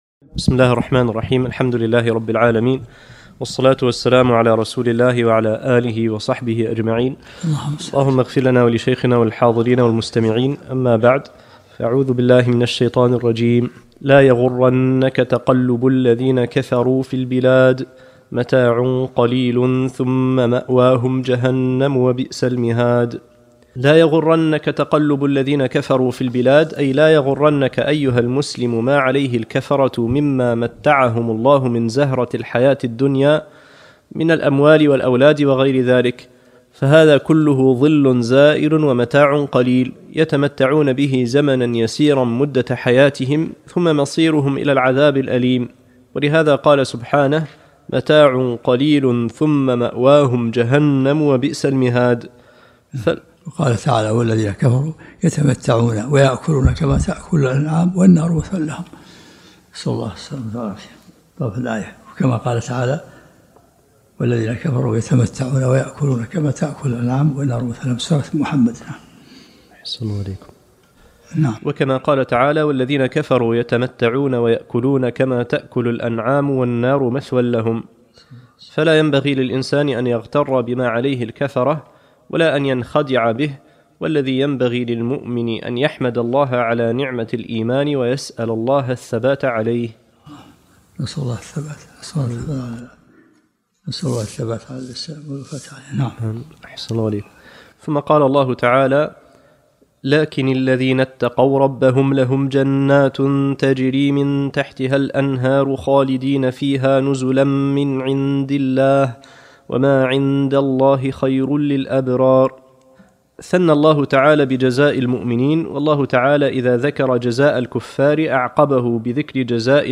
الدروس العلمية